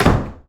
door_close_slam_01.wav